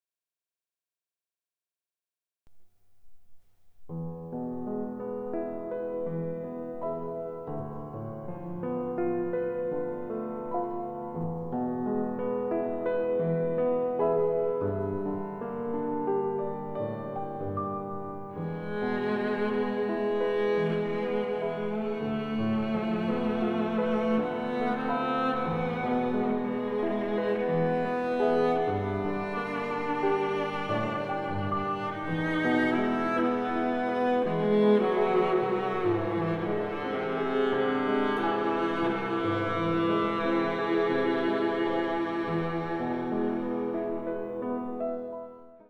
ヴィオラ